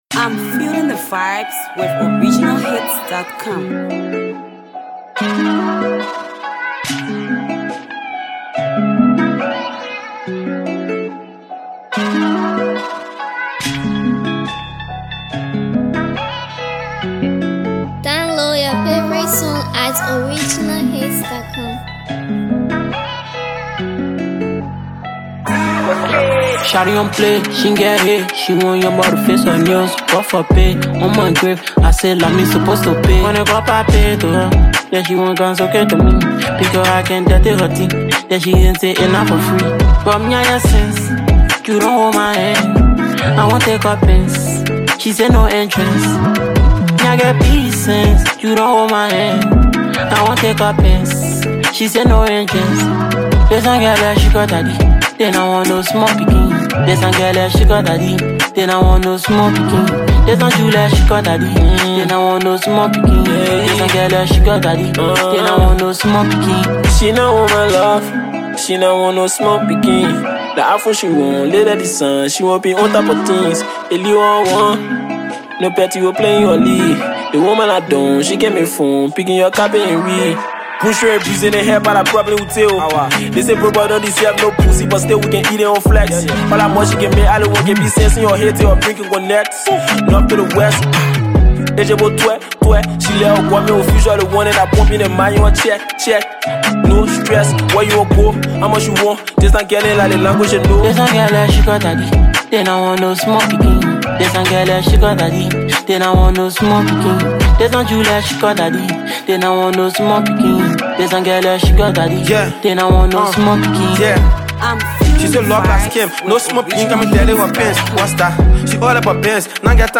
RnB x Trapco & Hipco